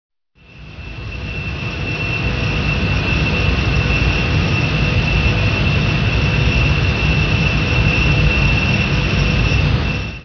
gas-refinery.ogg